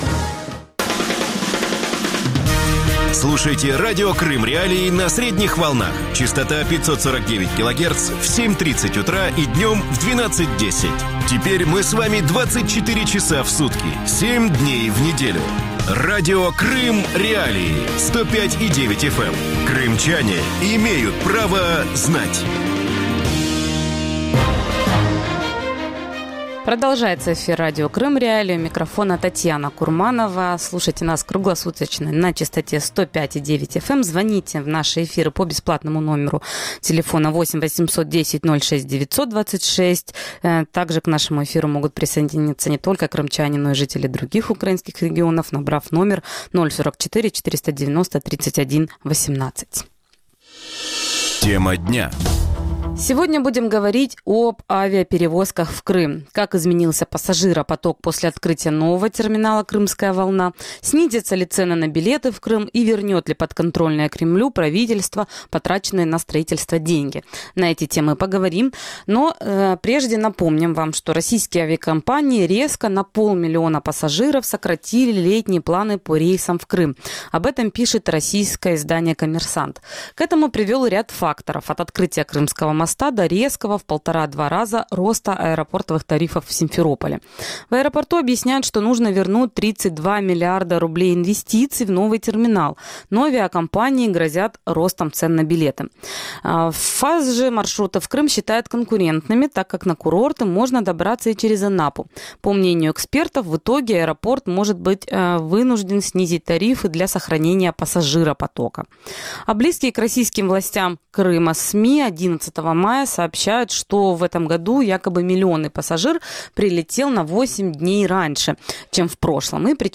Почему количество авиарейсов в Крым сократилось? Как изменился пассажиропоток после открытия нового терминала симферопольского аэропорта? Снизятся ли цены на авиабилеты в Крым? Гости эфира